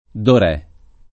[ dor $+ ]